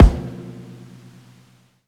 live_kickwq.wav